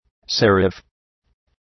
Shkrimi fonetik {‘serəf}